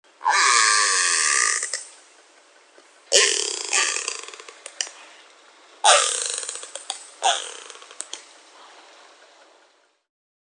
Чистый голос косатки